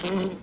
fly_buzz_flying_05.wav